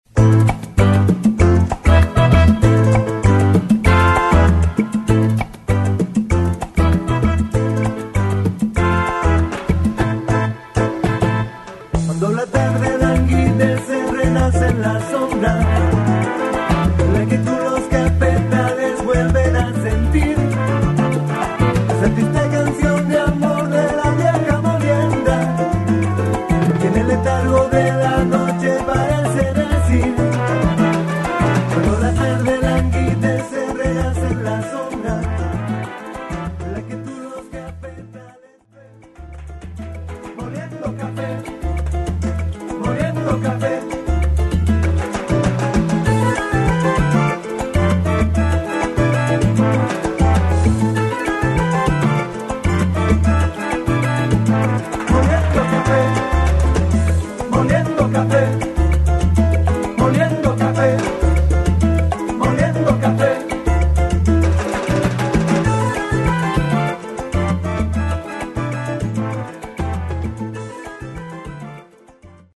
Category: salsa
Style: mambo
Solos: *vocal, no pregón